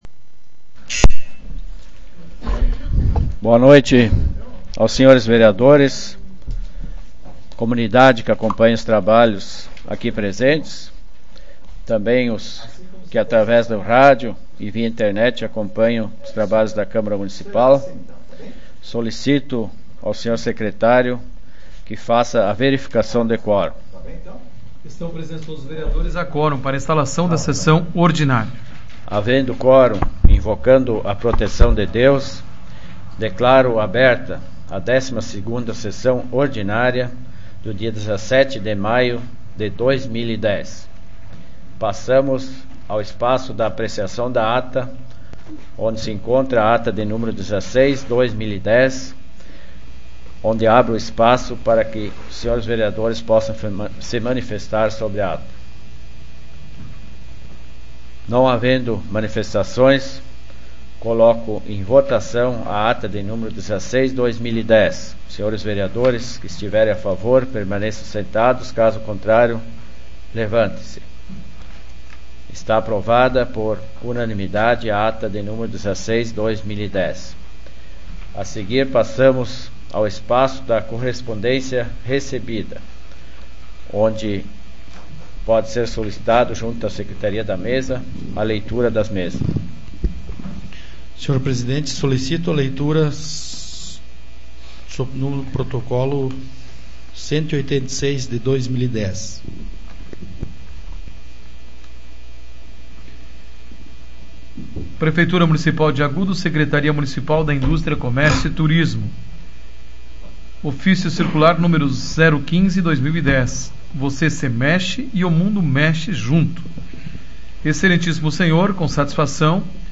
SAPL - Câmara Municipal de Agudo
Tipo de Sessão: Ordinária